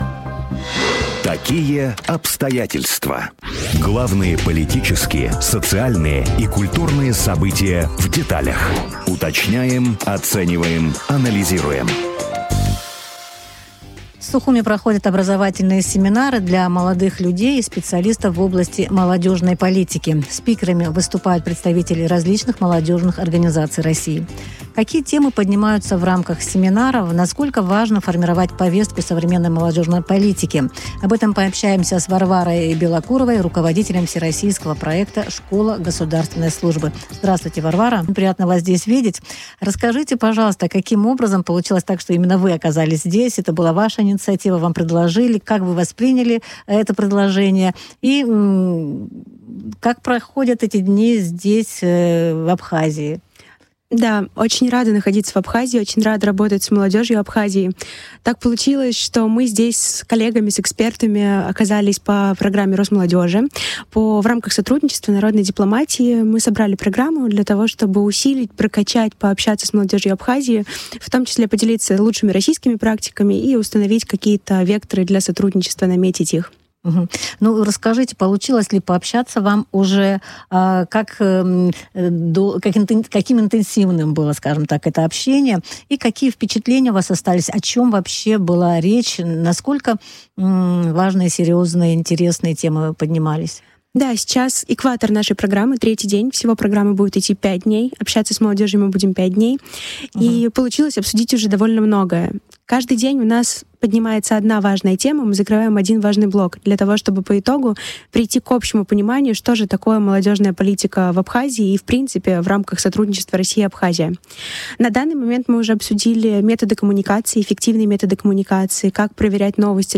в эфире радио Sputnik рассказала об образовательных семинарах для молодых людей и специалистов в области молодежной политики.